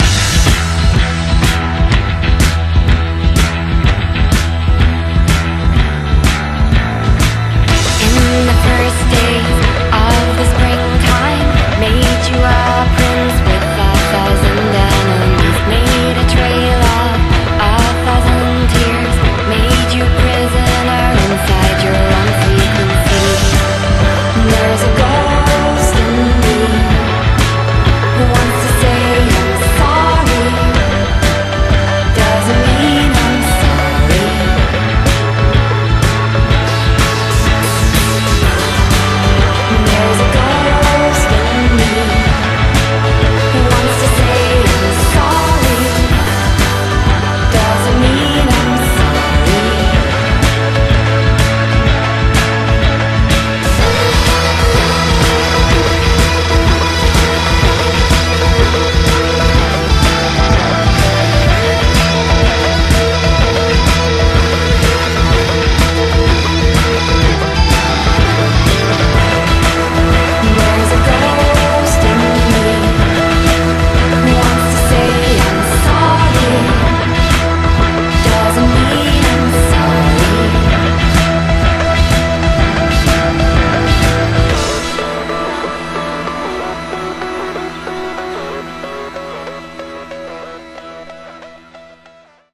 BPM125
Audio QualityCut From Video